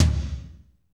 TOM S S L0XR.wav